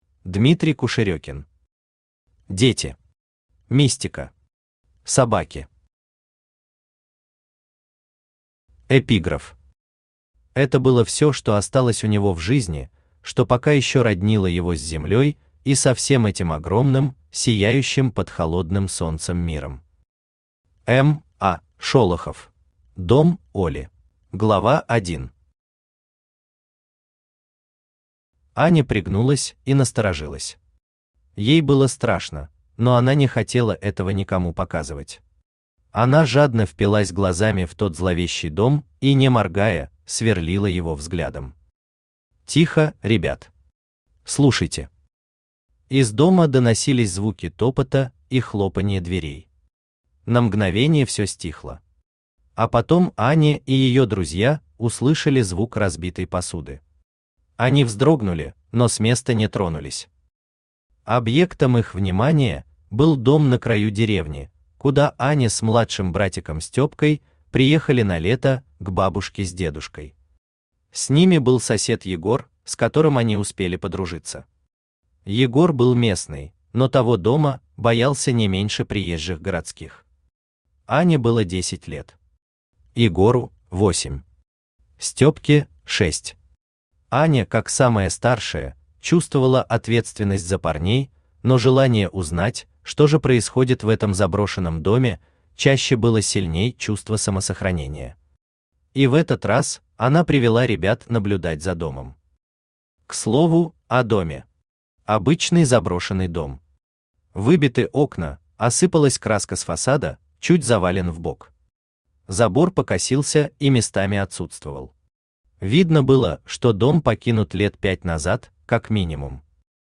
Аудиокнига Дети. Мистика. Собаки | Библиотека аудиокниг
Собаки Автор Дмитрий Кушерекин Читает аудиокнигу Авточтец ЛитРес.